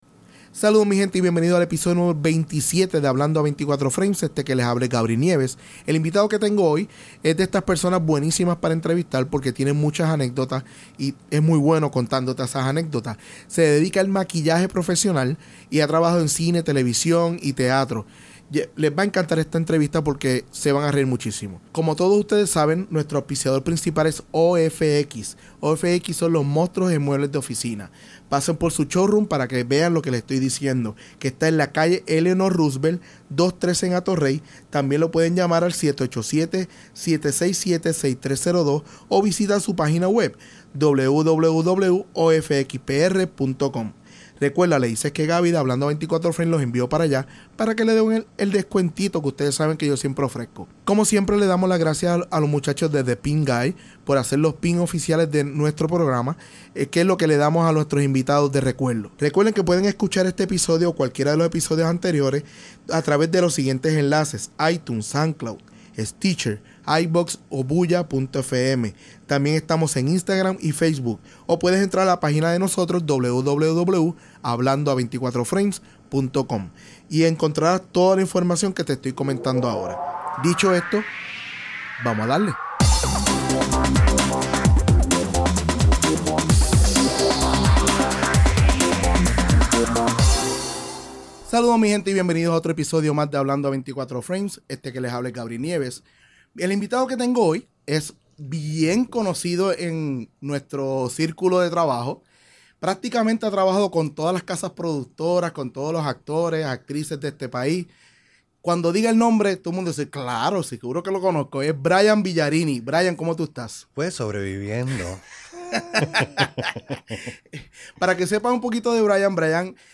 En el episodio #27 hablo con un profesional del arte de maquillaje. A laborado en cine, tv y sobre todo teatro. Fue una conversación super amena y la disfrute mucho.